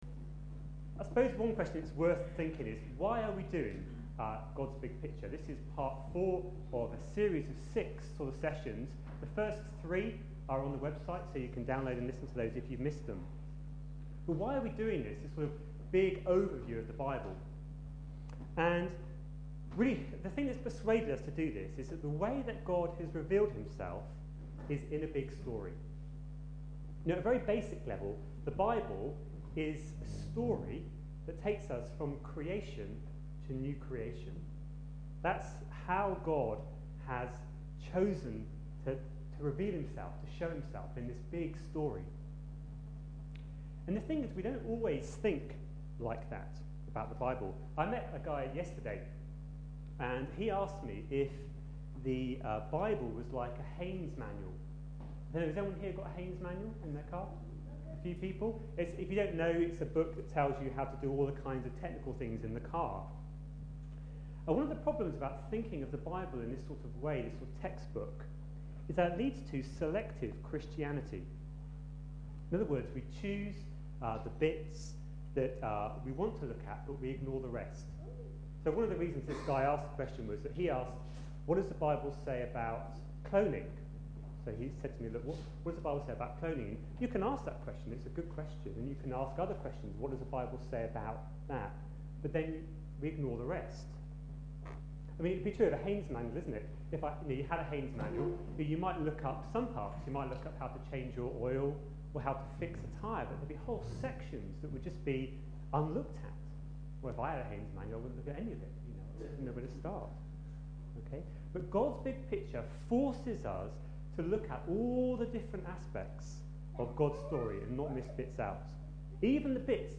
A sermon preached on 31st October, 2010, as part of our God's Big Picture series.